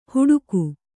♪ huḍuku